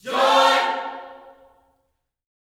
JOY CHORD4.wav